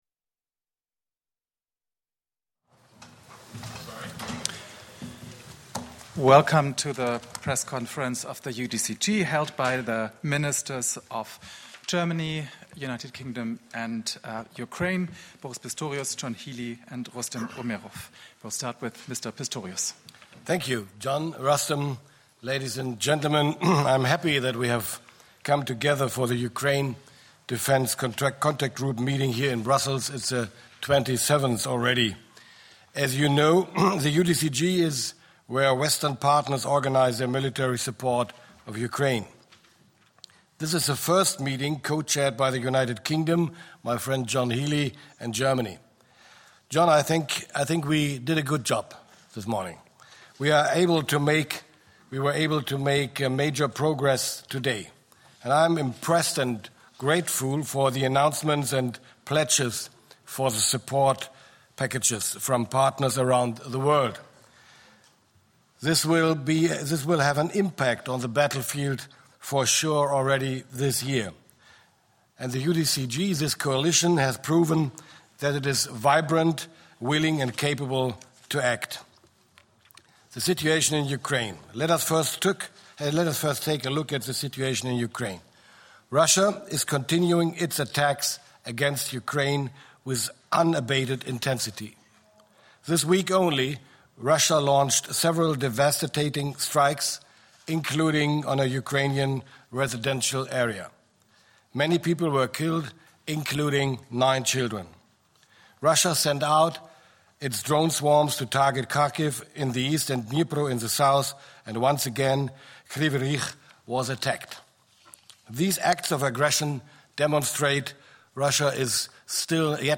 ENGLISH - Opening remarks by UK Secretary of State for Defence John Healey and the Defence minister of Germany, Boris Pistorius at the Meeting of the Ukraine Defence Contact Group.